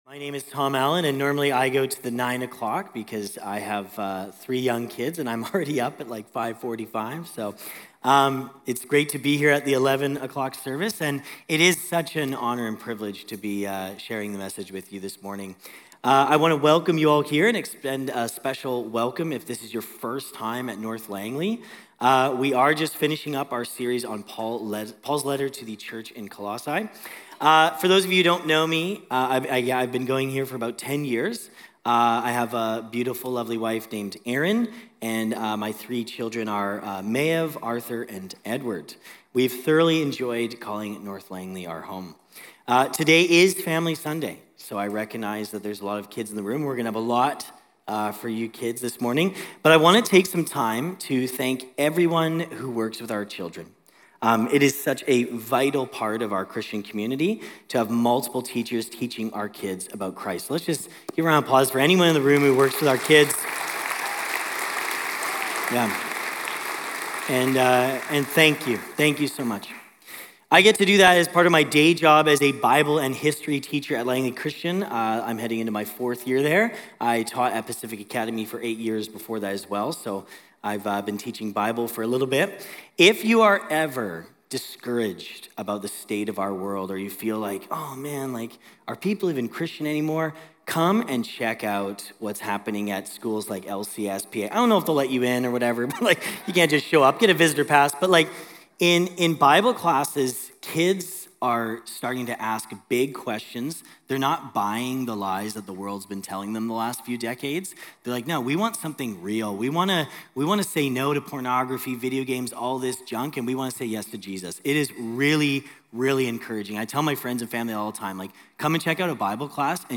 Messages from the Walnut Grove campus of North Langley Community Church in Langley, BC, Canada.